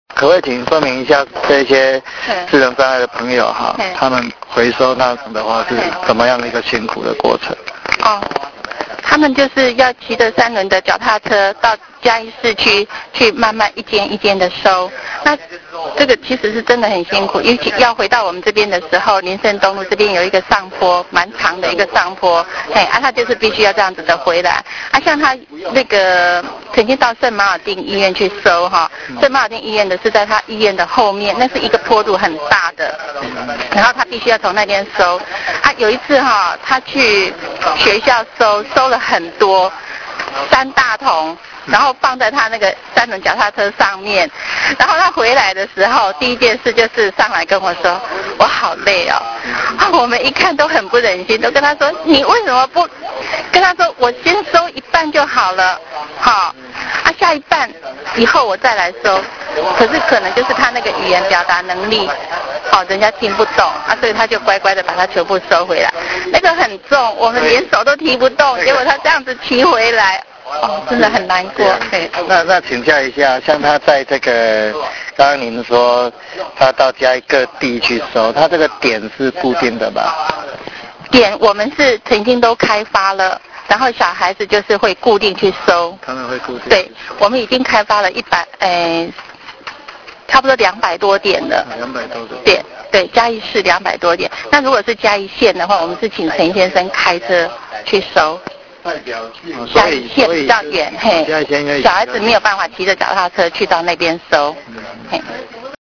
我聽她以輕輕柔柔的聲音